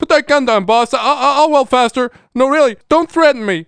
1 channel
welder-threat5.mp3